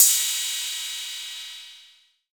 808-OpenHiHats01.wav